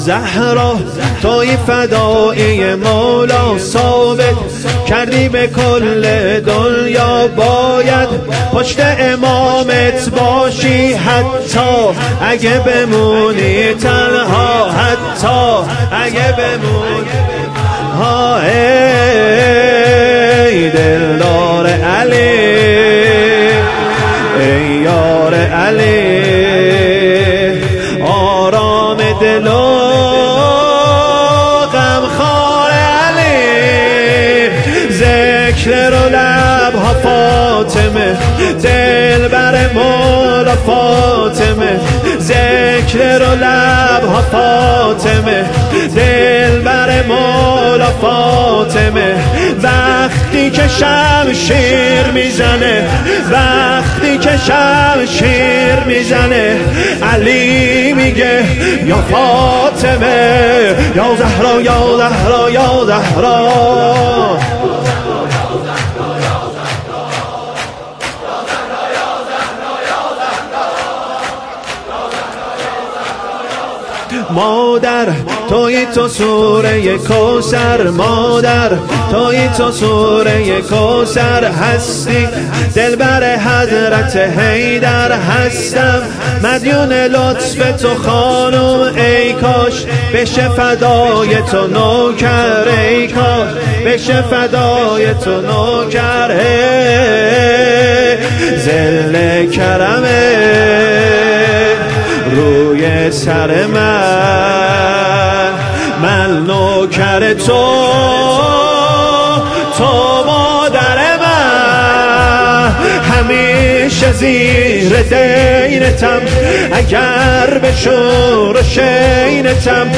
شور
سینه زنی شور
ایام فاطمیه دوم - شب اول